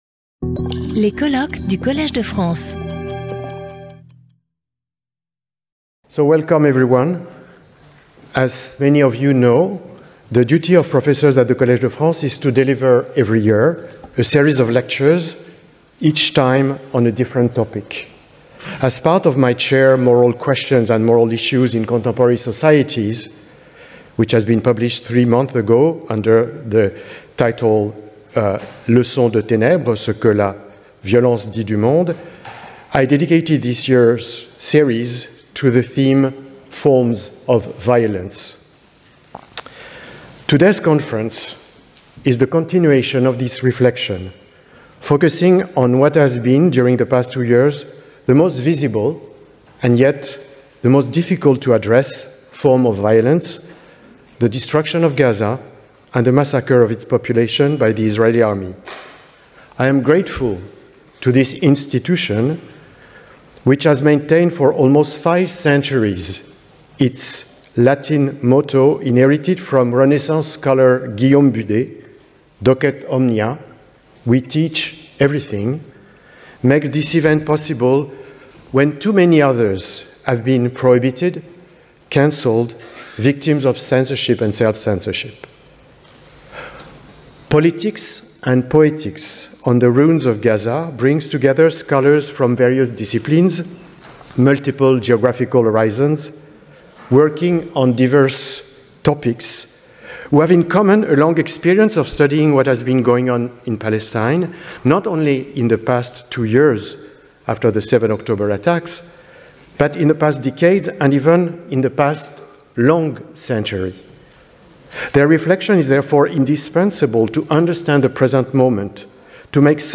Skip youtube video player Listen to audio Download audio Audio recording Cette vidéo est proposée dans une version doublée en français.
Symposium